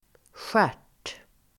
Ladda ner uttalet
Uttal: [sjär_t:]